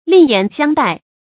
另眼相待 lìng yǎn xiāng dài
另眼相待发音